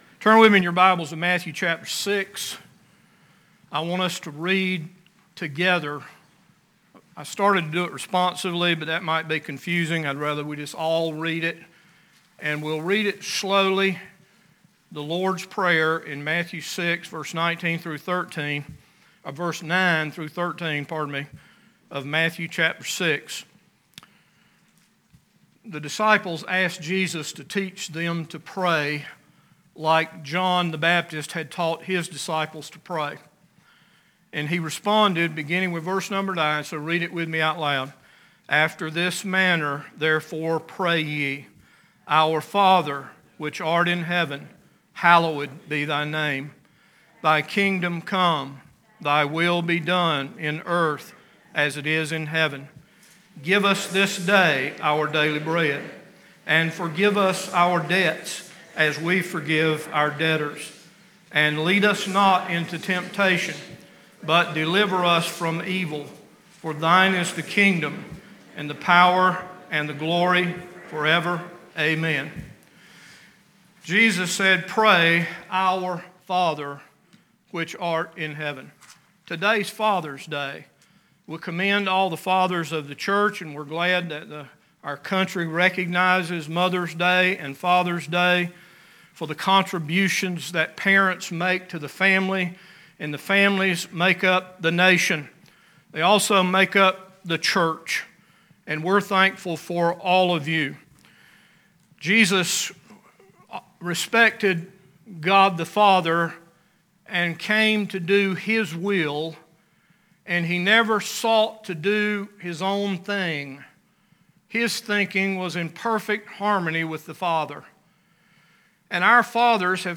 Sunday AM 6/20/2021 – Bible Baptist Church